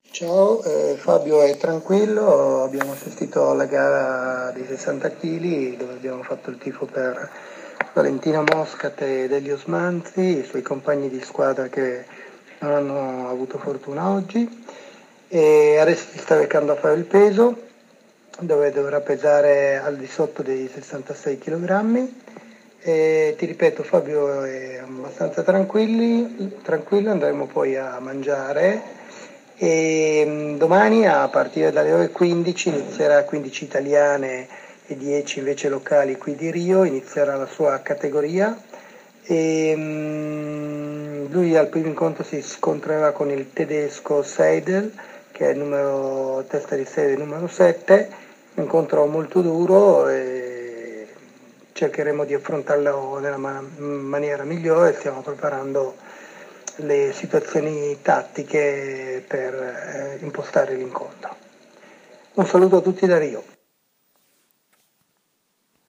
Abbiamo ricevuto da Rio un messaggio vocale